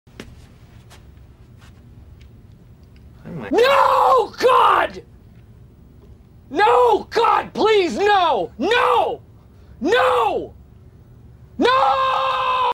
Звук крика No актера из сериала Офис